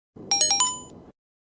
Gta v notification
gta-v-notification-soundbuttonsboard.net_.mp3